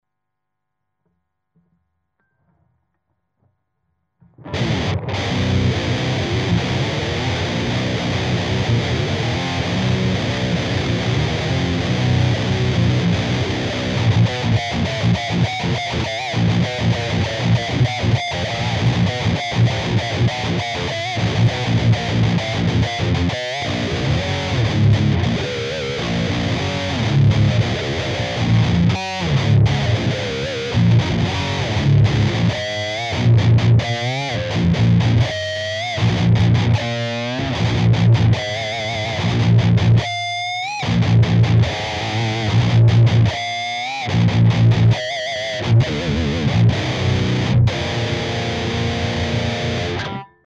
примеров звука именно этого типа перегруза ("Metall") не меняя ручек настроек, а меняя лишь типы кабинетов.
Metall+BLK2x12
(Гитара Ibanez RG-270, строй Drop C , струны 13-68).
MetallBLK2x12.mp3